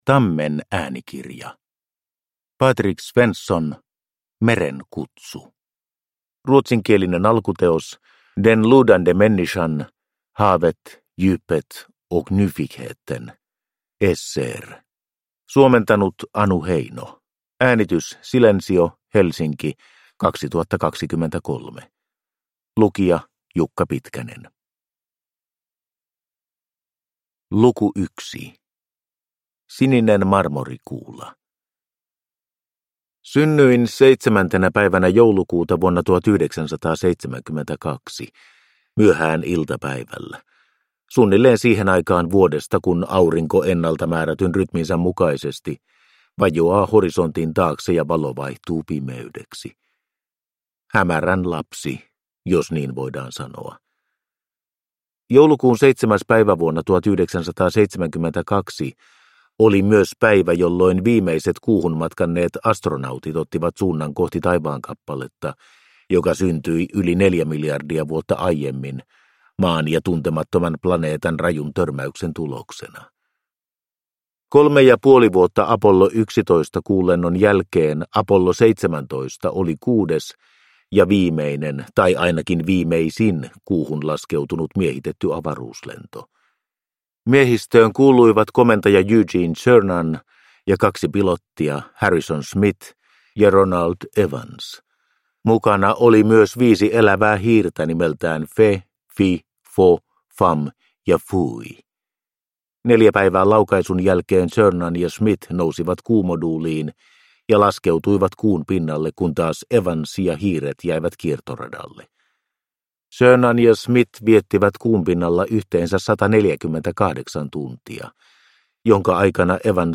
Meren kutsu – Ljudbok – Laddas ner